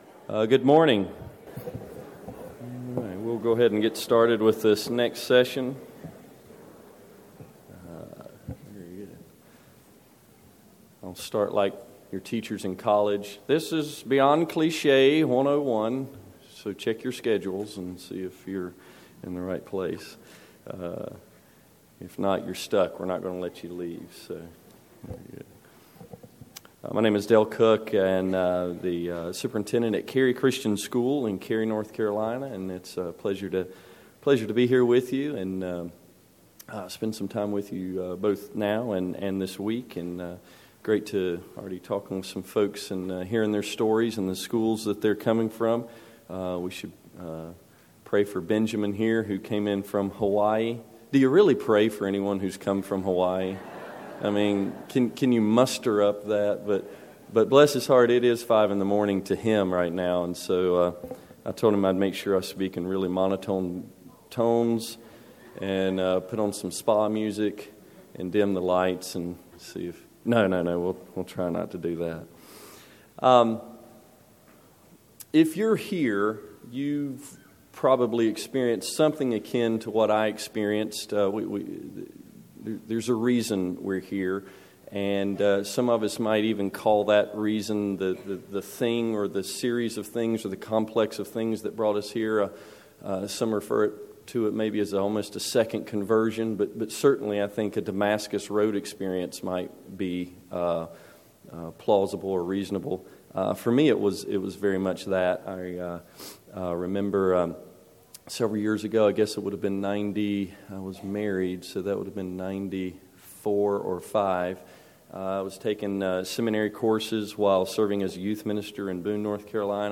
2012 Workshop Talk | 0:52:28 | All Grade Levels, Culture & Faith
This workshop will look at how the unique claims of the Christian faith work their way into the warp and woof of the dialectic and rhetoric classroom and curriculum. Speaker Additional Materials The Association of Classical & Christian Schools presents Repairing the Ruins, the ACCS annual conference, copyright ACCS.